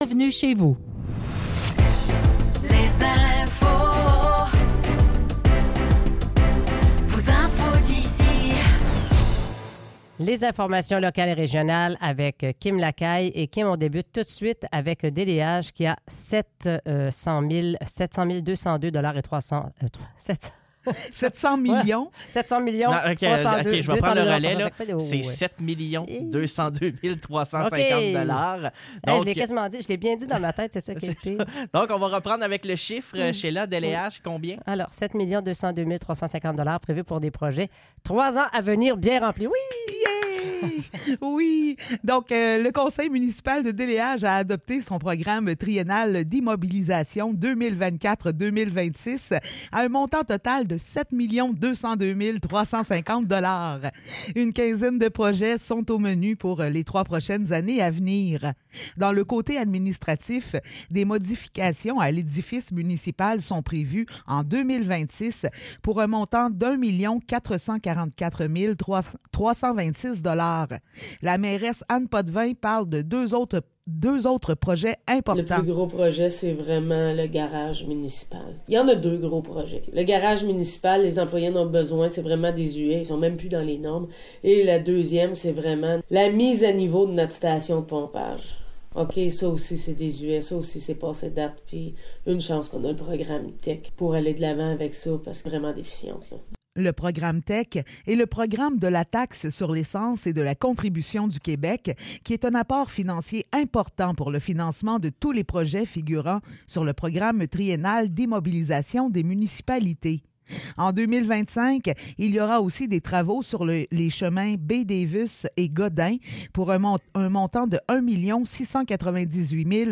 Nouvelles locales - 16 janvier 2024 - 8 h